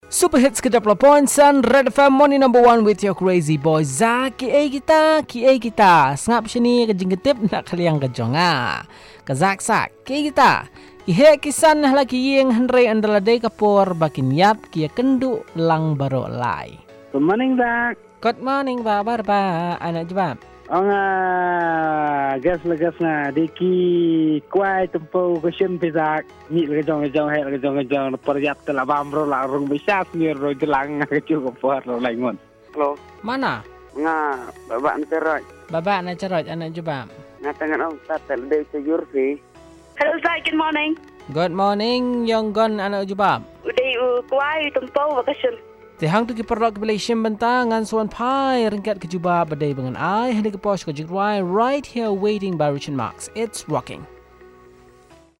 Callers